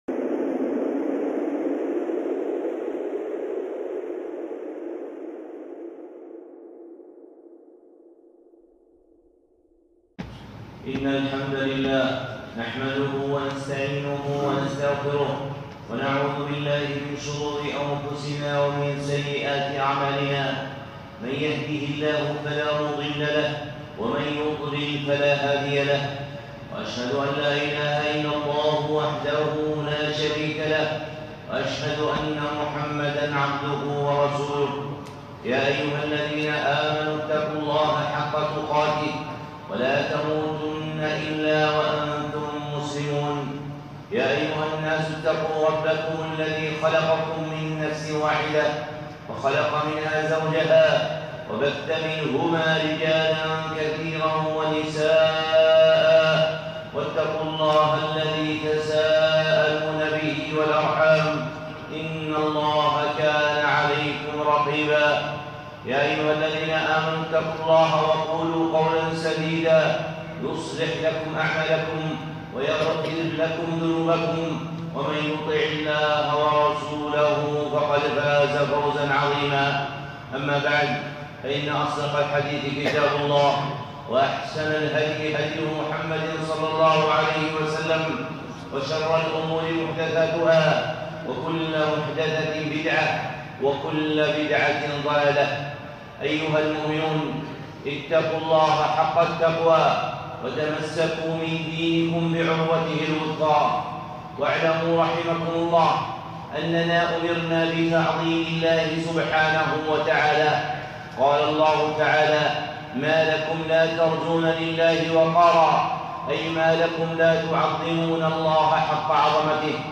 خطبة (تنديد الحالفين) الشيخ صالح العصيمي